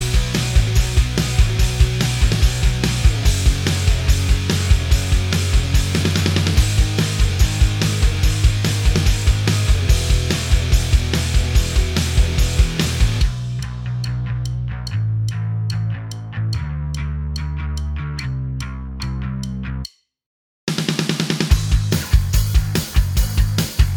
Minus Main Guitars Indie / Alternative 3:07 Buy £1.50